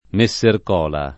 [ me SS erk 0 la ]